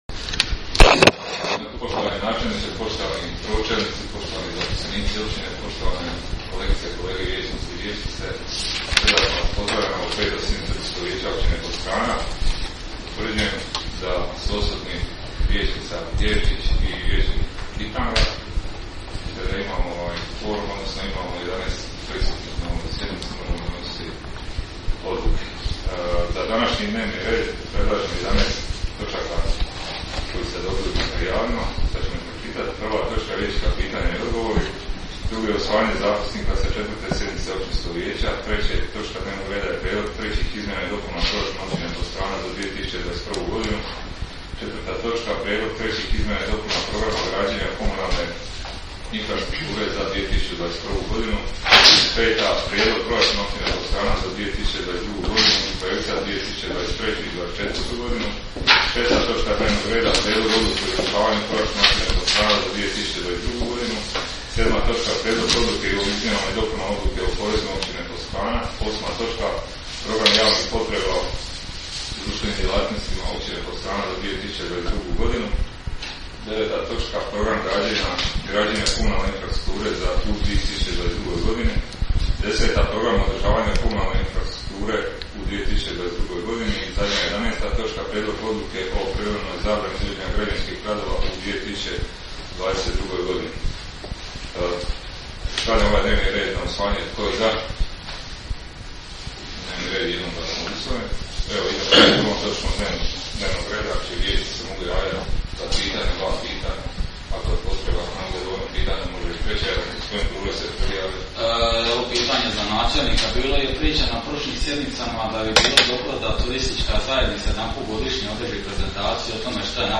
Audio zapis sa 5. sjednice Općinskog vijeća Općine Podstrana, održane dana 8. prosinca 2021. godine u sali za sastanke Općine Podstrana